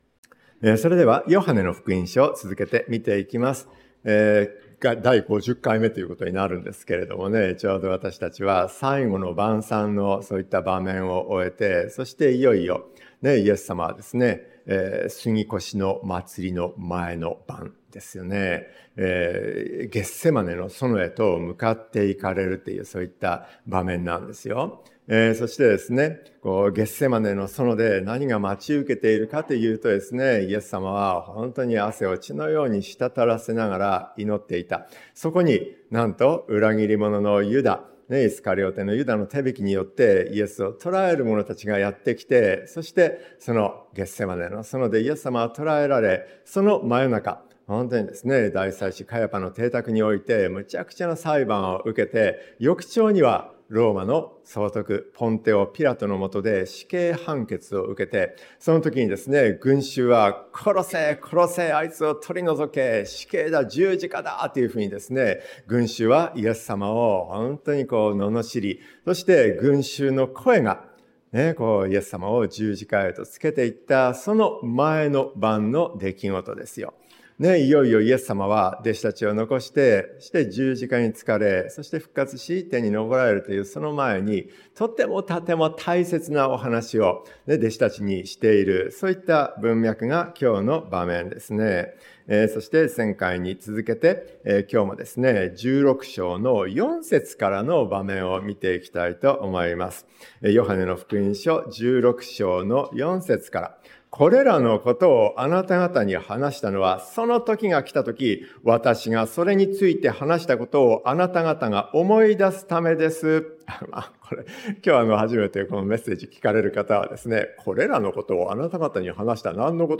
真理に導く聖霊の働き 説教者